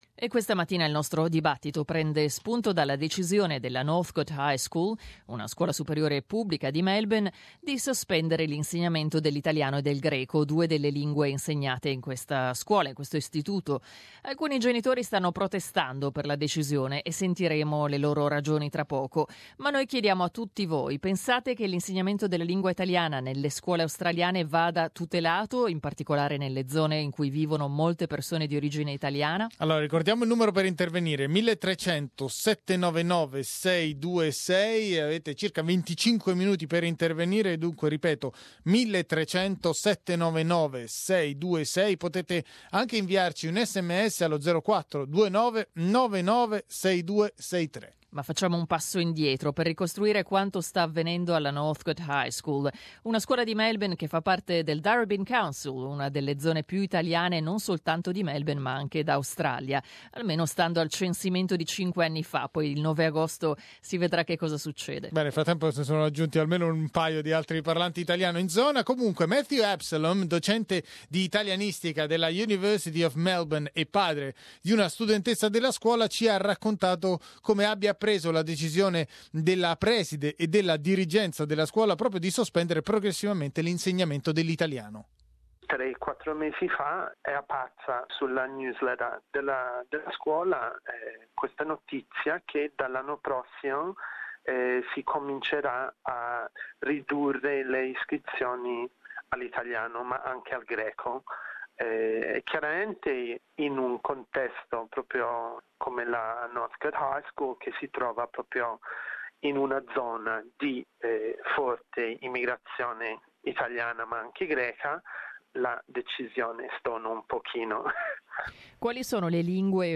Ne abbiamo parlato con i nostri ascoltatori.